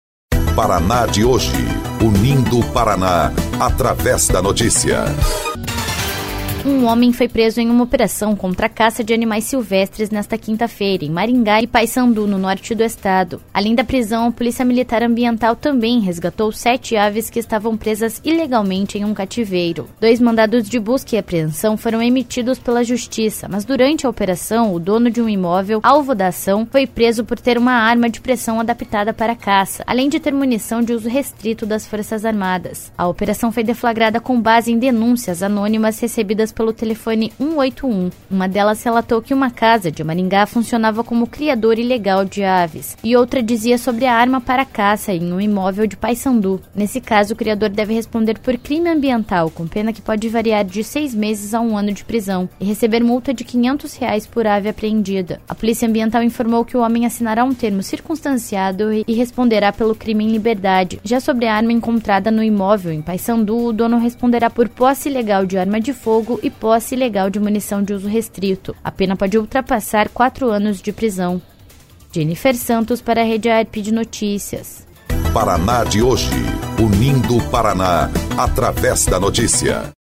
07.06 – BOLETIM – Homem é preso em operação contra caça de animais silvestres no norte do Paraná